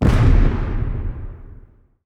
explosion_medium.wav